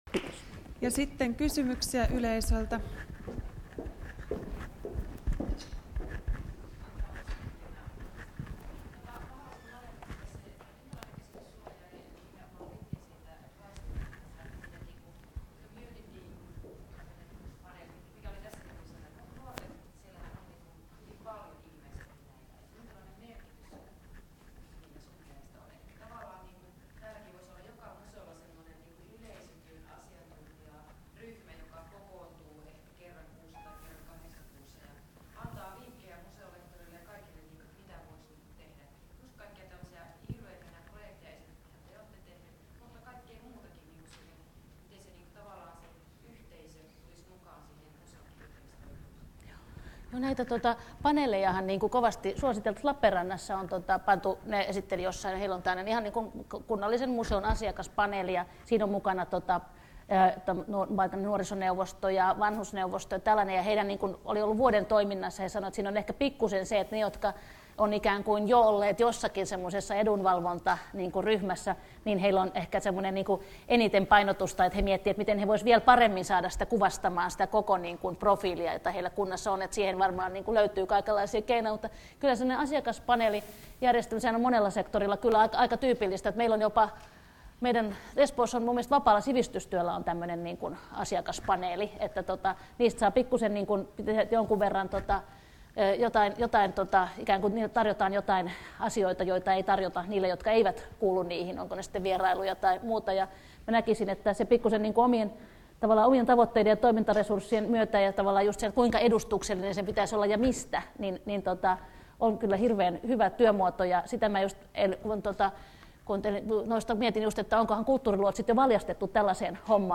Keskustelu / Helinä Rautavaaran museo